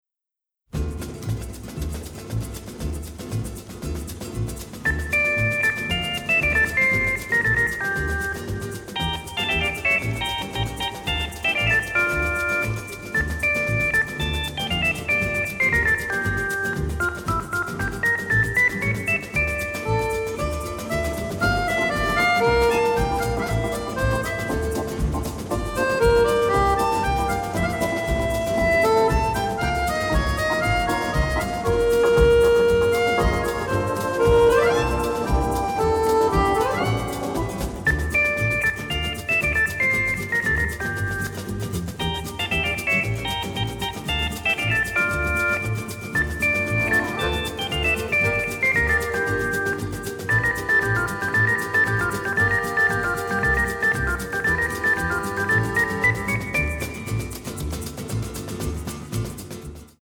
The Soundtrack Album (stereo)